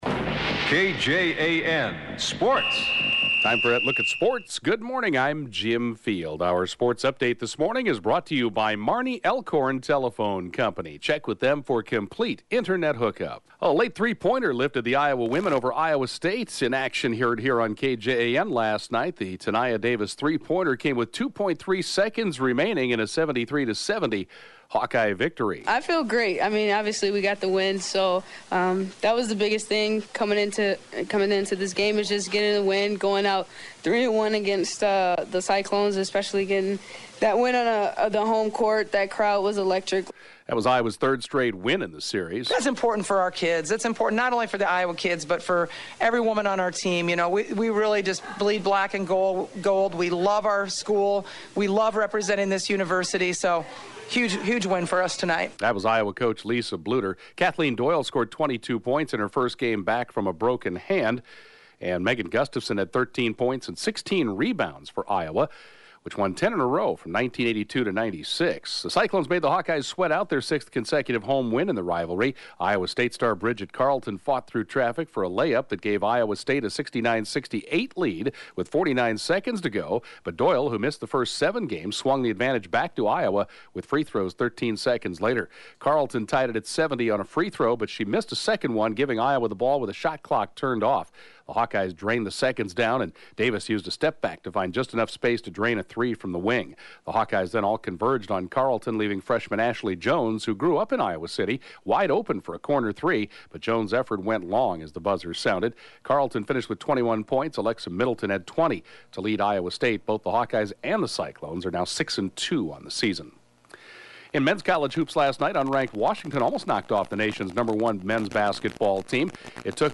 The 7:20-a.m. Sportscast